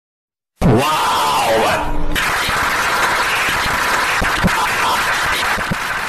Sound Effects
Fast Claps